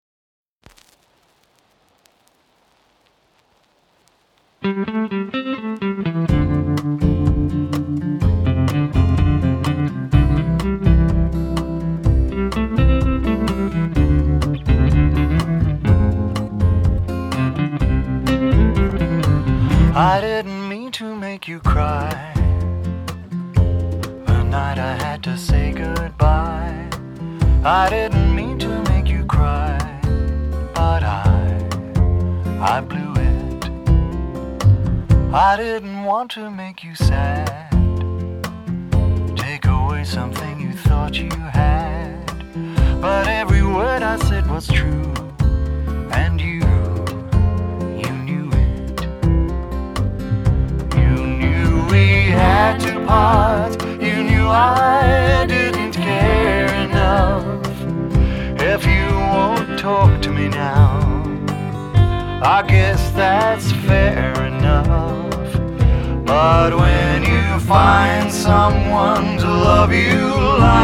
★ 來自澳洲的民謠爵士三人組清新自然的民謠演繹，帶給您舒服暢快的聆聽享受！